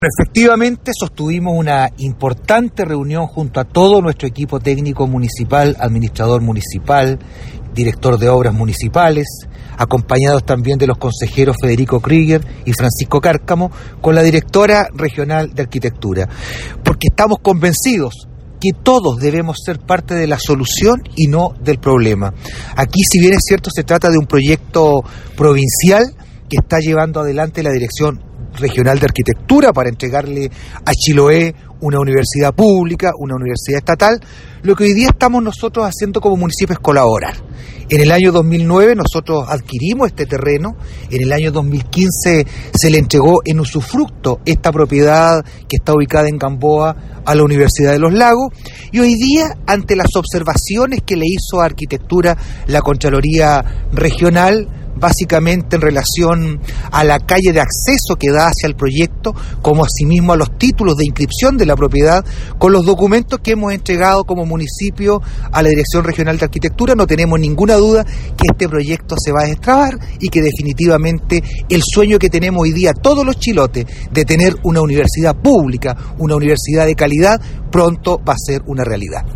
cuña-alcalde-vera-tema-universidad.mp3